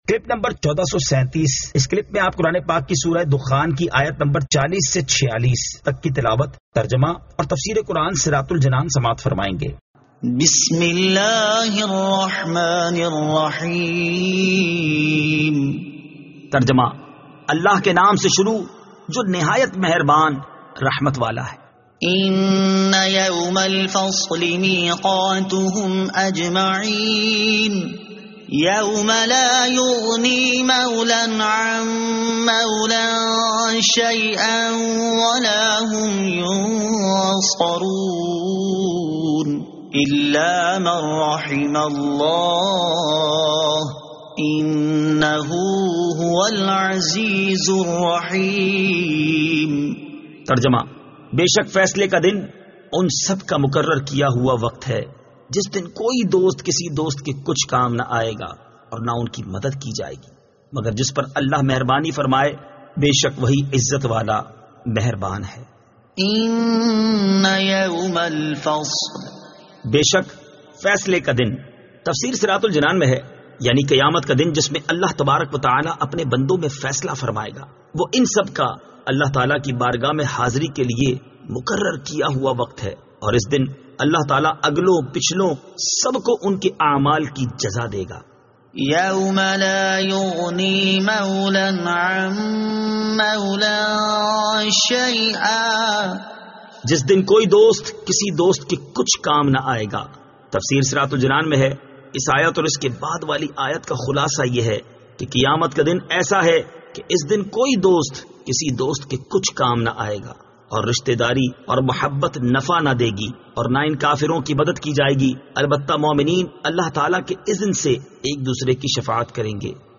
Surah Ad-Dukhan 40 To 46 Tilawat , Tarjama , Tafseer